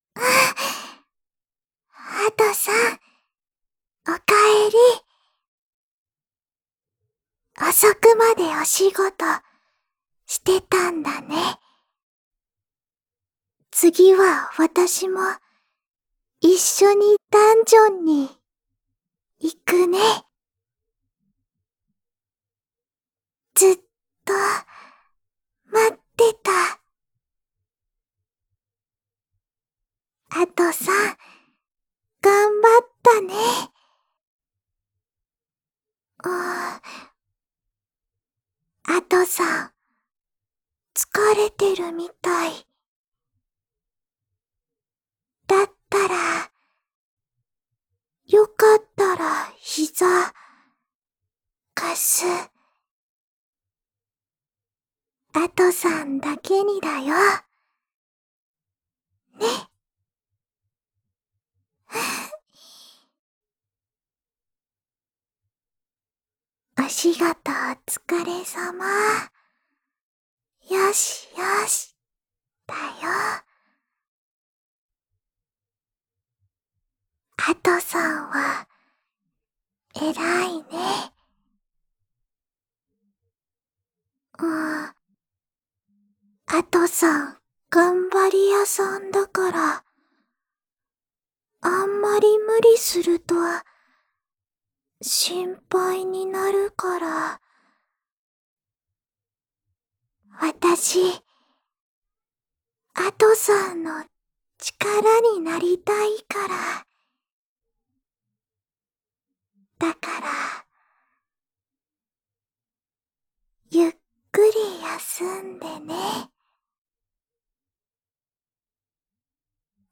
アトさん専用甘やかボイス | PandoraPartyProject